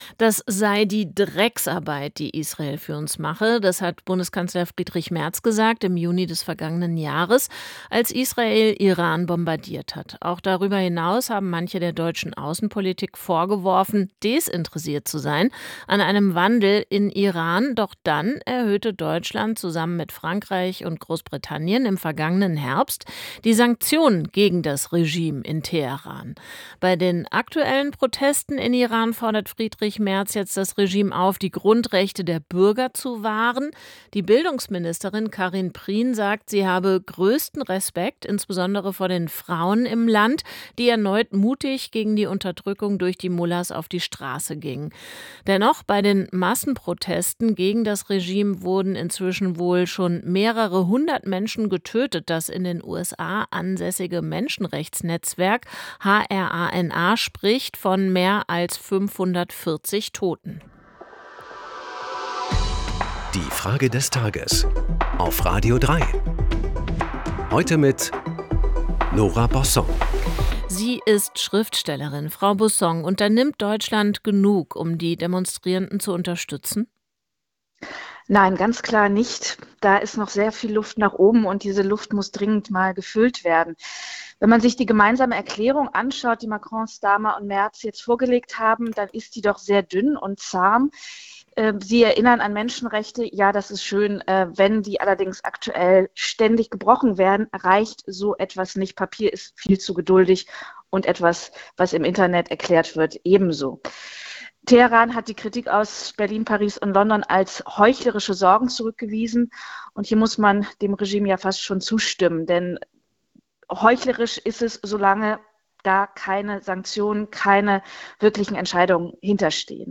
die Schriftstellerin Nora Bossong.